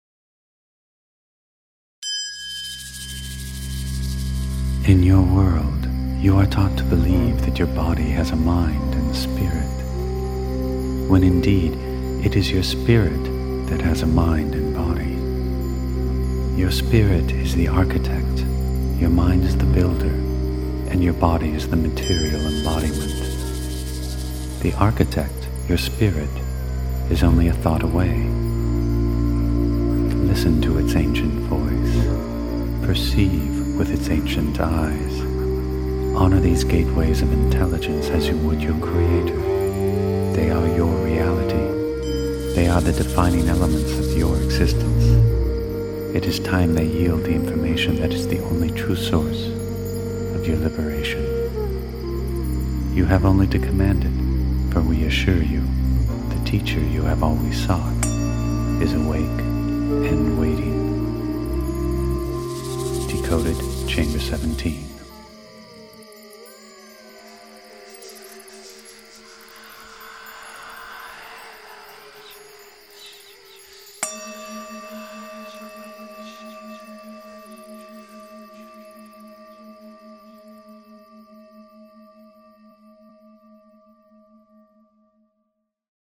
Filosofia Inediti – Audiolettura